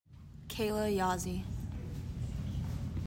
Pronunciation : KAY la YA zee